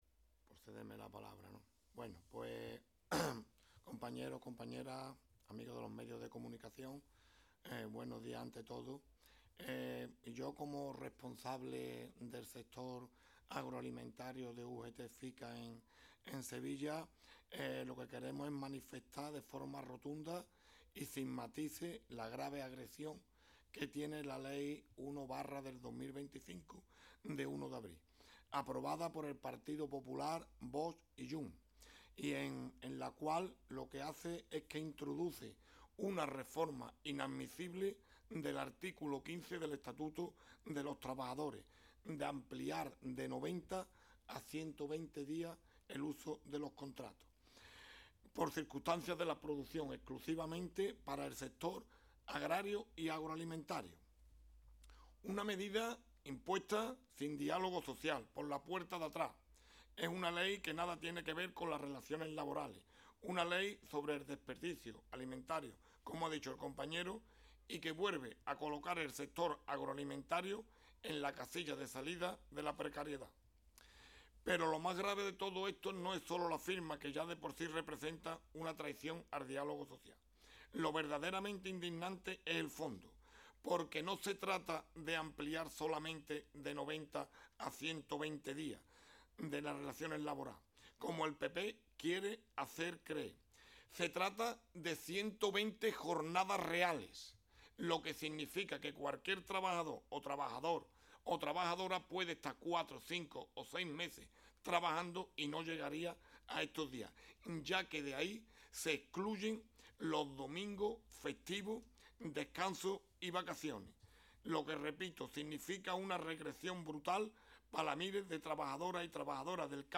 Las federaciones andaluzas de UGT FICA y CCOO Industria han ofrecido hoy una rueda de prensa conjunta en la sede de CCOO Andalucía para explicar los motivos de la concentración convocada para mañana martes, 13 de mayo, a las 11:00 horas frente a la sede del Partido Popular en Sevilla (C/ San Fernando, 39).